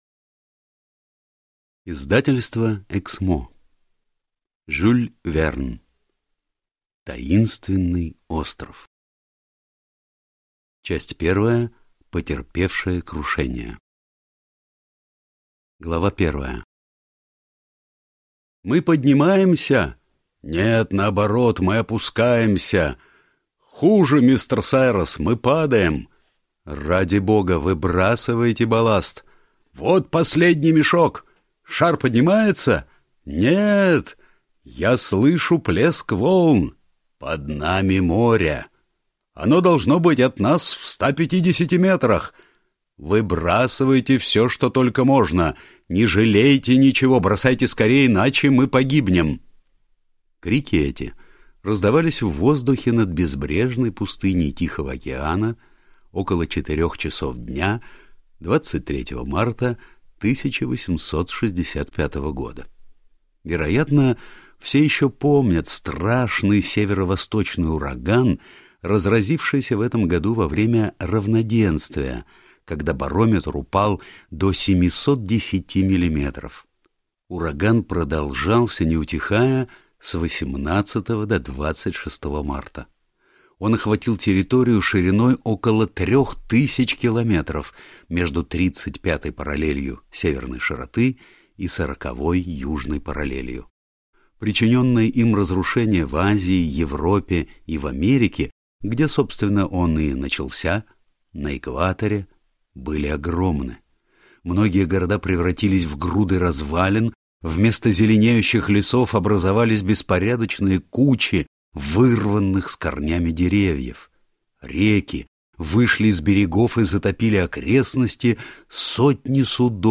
Аудиокнига Таинственный остров | Библиотека аудиокниг
Прослушать и бесплатно скачать фрагмент аудиокниги